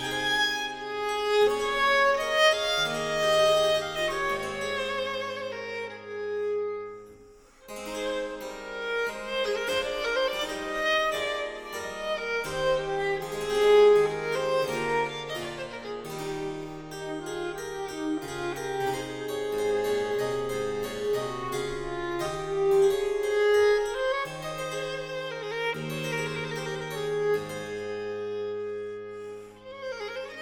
la majeur